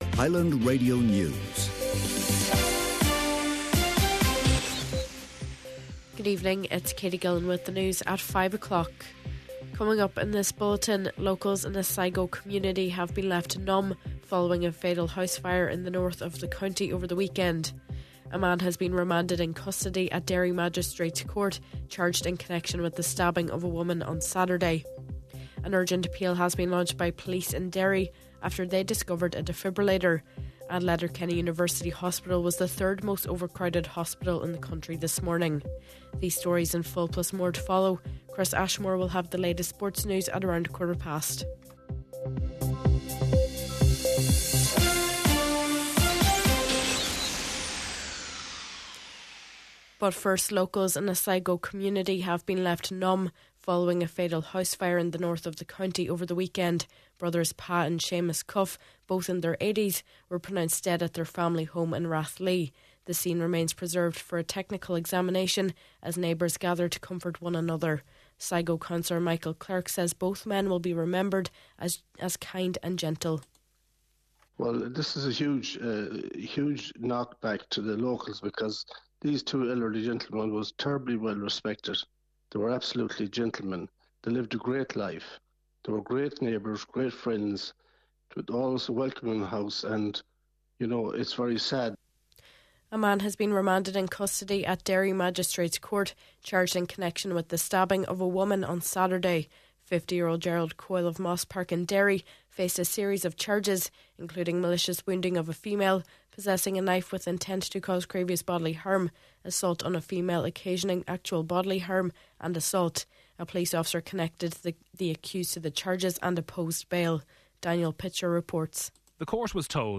Main Evening News, Sport and Obituary Notices – Monday December 29th